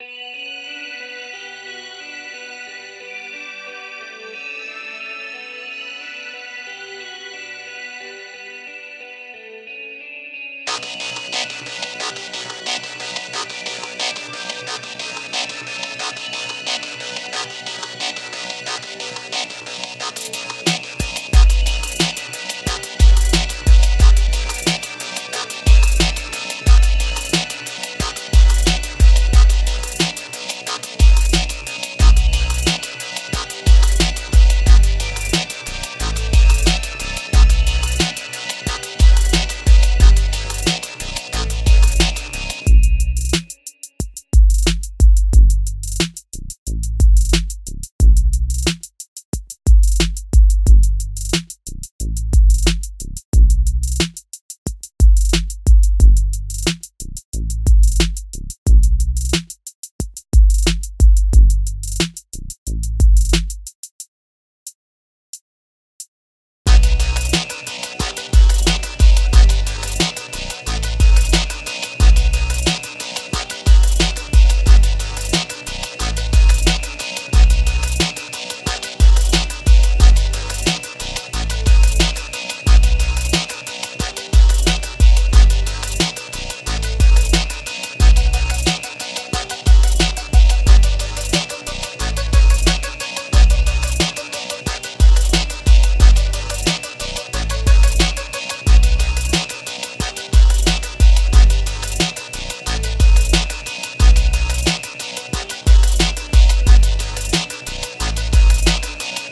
Neuer Song [HipHop/Instrumental] Feedback zum Mix
Hallo Leute, ich arbeite gerade an einem Song mit Sprechgesang und mich würde interessieren wie bisher der Mix ist. Vor allem der Bass.